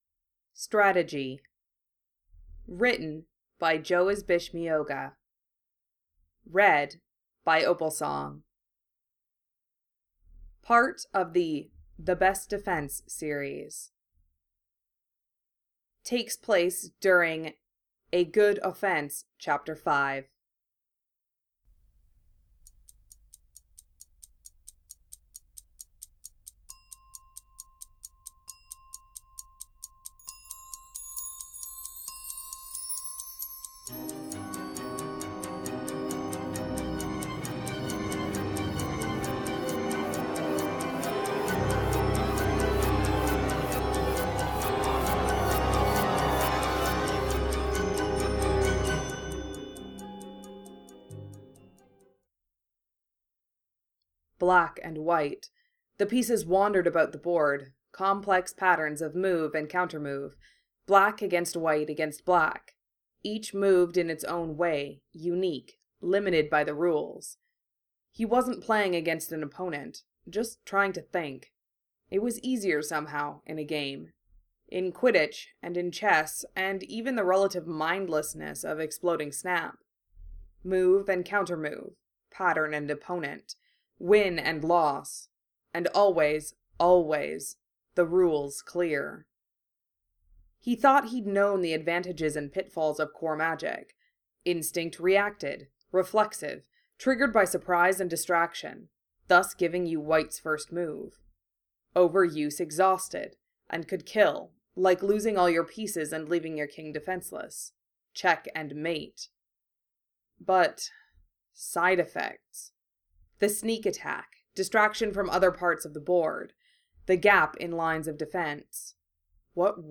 Music: Forward Time to the Past by John Williams